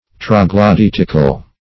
Search Result for " troglodytical" : The Collaborative International Dictionary of English v.0.48: Troglodytic \Trog`lo*dyt"ic\, Troglodytical \Trog`lo*dyt"ic*al\, a. [L. troglodyticus, Gr. ?.] Of or pertaining to a troglodyte, or dweller in caves.